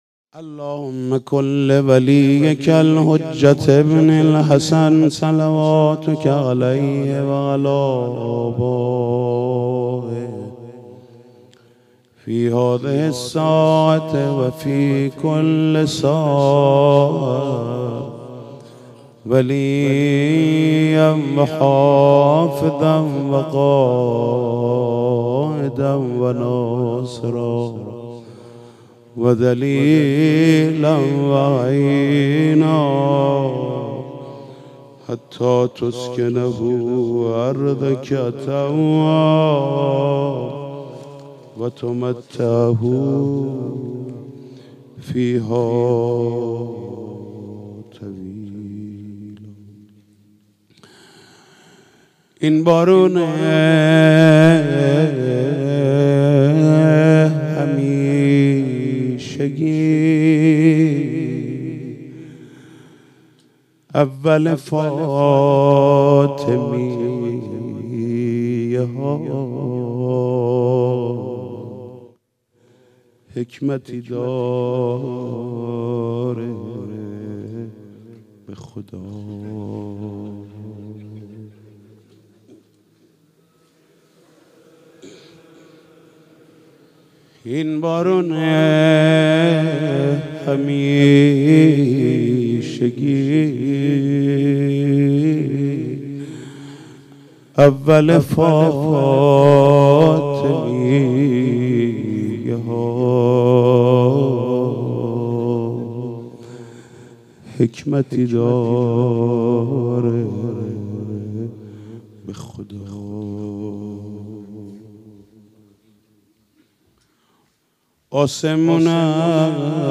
مداحی حاج محمود کریمی و حاج سید مجید بنی فاطمه در رایة العباس(ع) + گزارش صوت
مراسم عزاداری بی بی حضرت زهرا سلام الله علیها با سخنرانی حجت الاسلام علوی تهرانی و مداحی حاج محمود کریمی و حاج سید مجید بنی فاطمه در امامزاده علی اکبر چیذر عصر امروز (چهارشنبه) برگزار شد.